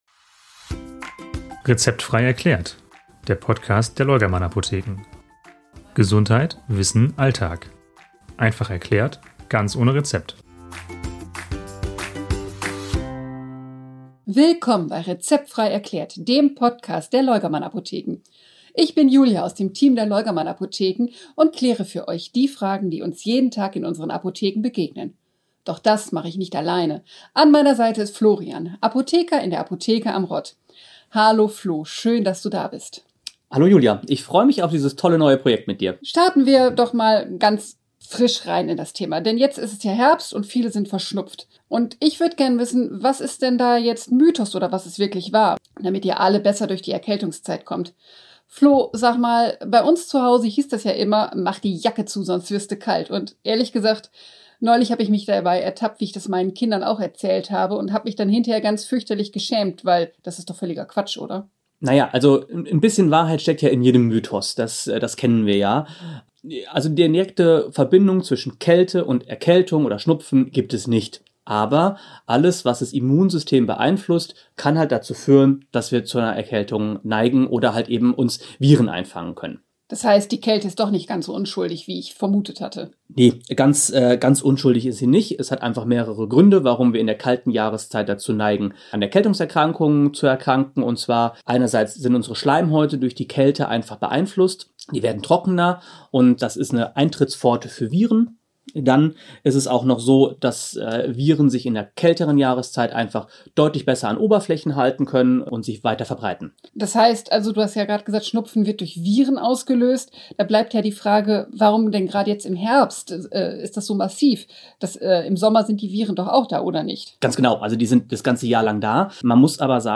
Wir räumen mit Mythen auf, sprechen über sinnvolle Nasenpflege und geben alltagsnahe Tipps, die ohne Verallgemeinerungen auskommen. Eine verständliche, unkomplizierte Folge – wie ein beratendes Gespräch in der Apotheke.